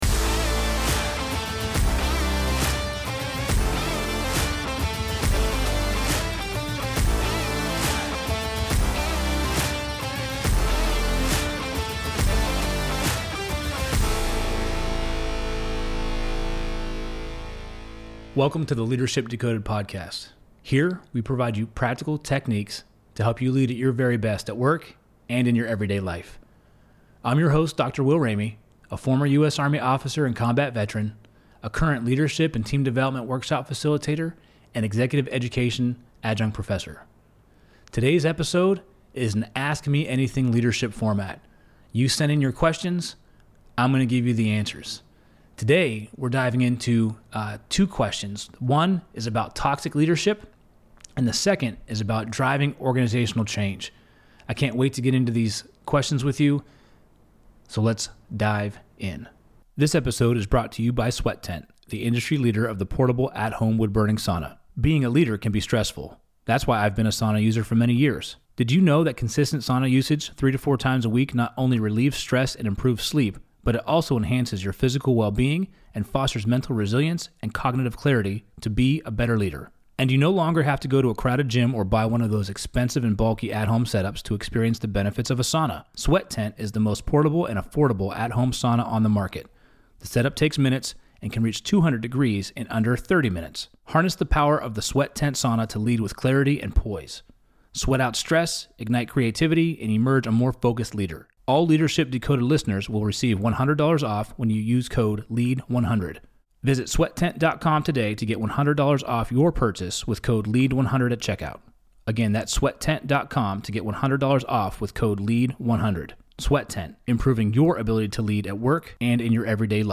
Ask Me Anything Leadership Q&A | Ep.044